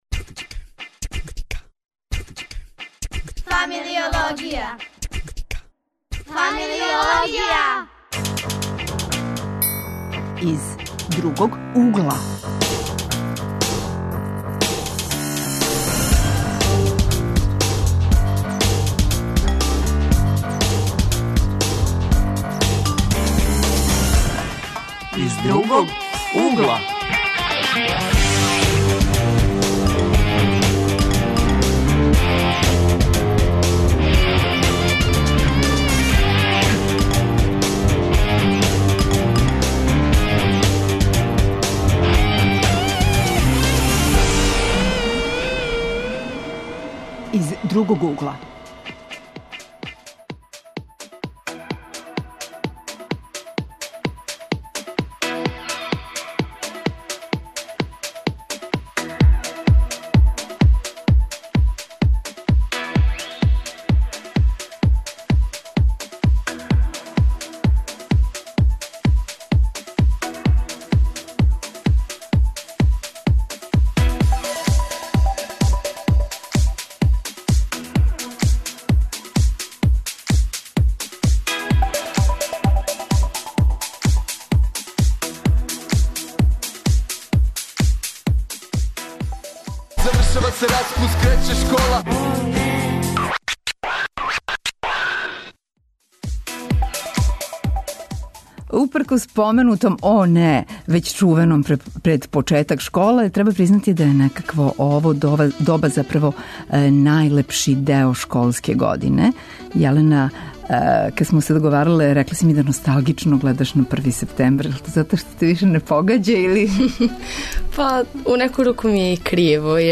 Школа је почела, а данас причамо о 1. септембру. Гости су нам млади из Уније средњошколаца.